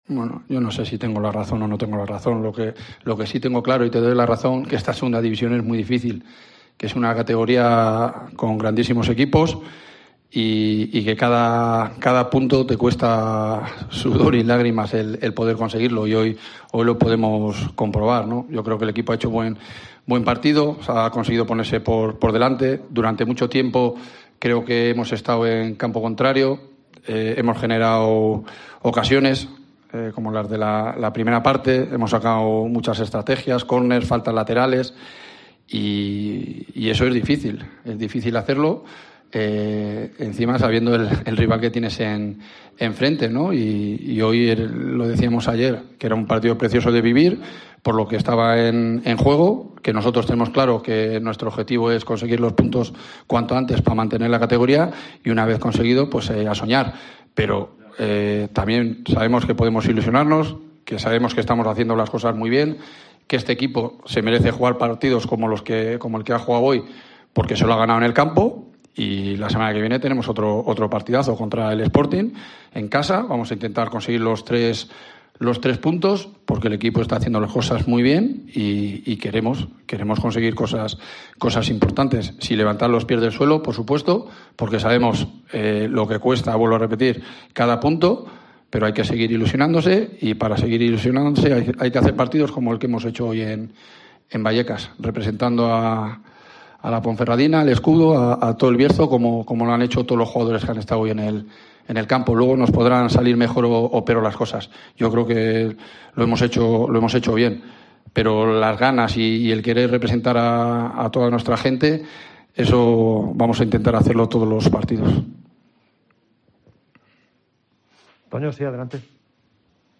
AUDIO: Escucha aquí las declaraciones del entrenador de la Deportiva Ponferradina, Jon Pérez Bolo, y del míster del Rayo Vallecano, Andoni Iraola